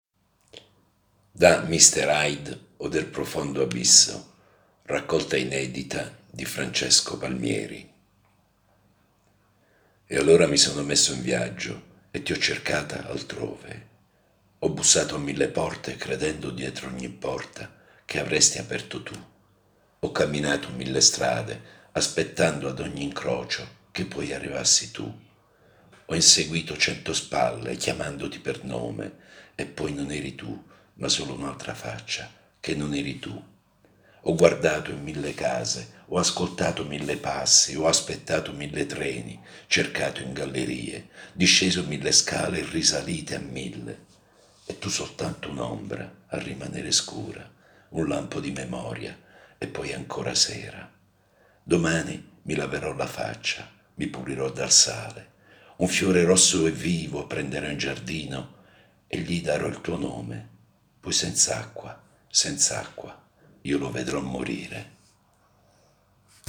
Solo un’avvertenza: la voce narrante è quella di un lettore comune e non l’espressione professionale di un attore, così come l’ambiente operativo che non è uno studio di registrazione.